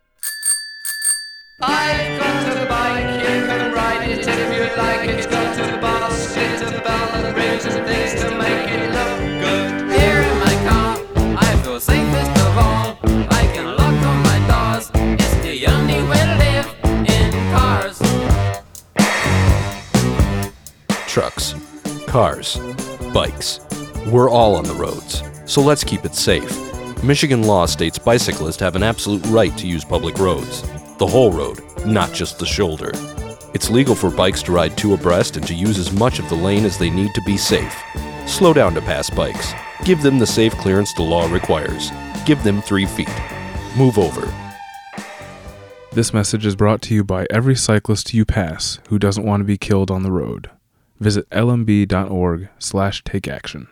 Don’t Kill a Cyclist – public service announcement